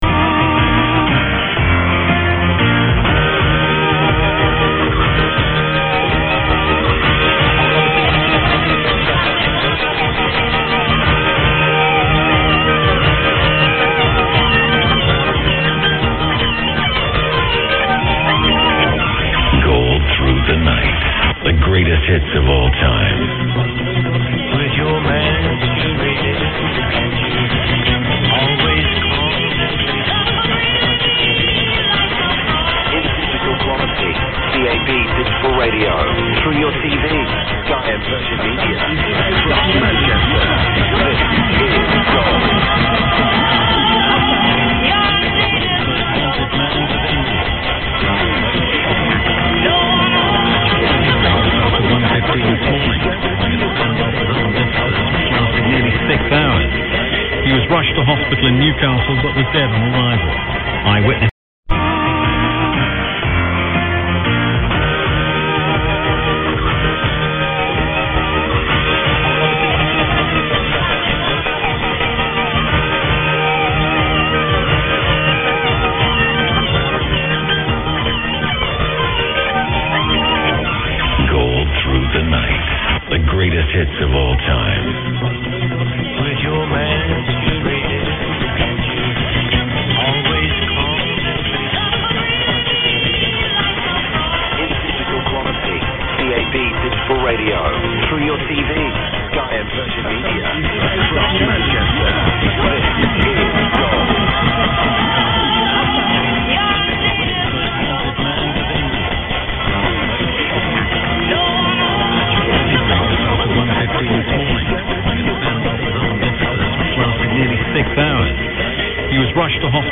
The clip below is of AM then SAM, using 10kHz settings for both recordings. There is a much bigger difference between SAM and AM on the WINRADIO than on the Perseus, on which debatably there is virtually NO difference.
excalibur_am_sam_810khz.mp3